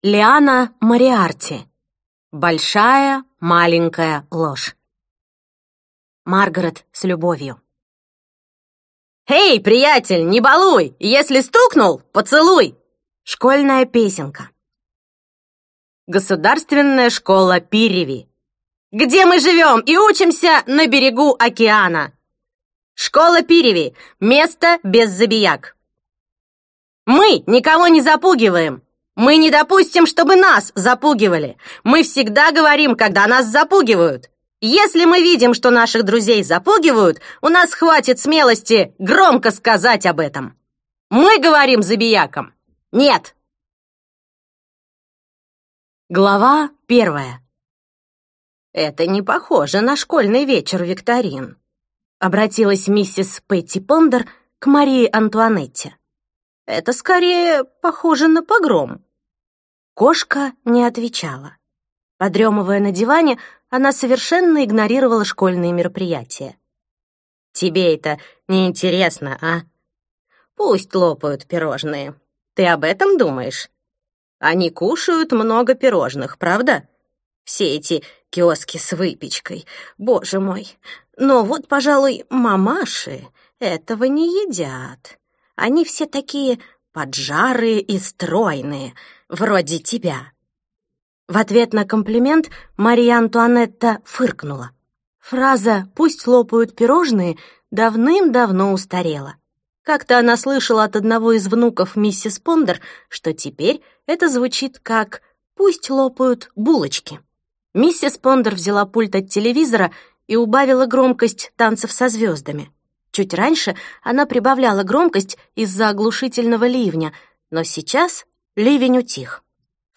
Аудиокнига Большая маленькая ложь - купить, скачать и слушать онлайн | КнигоПоиск